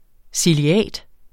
Udtale [ siliˈæˀd ]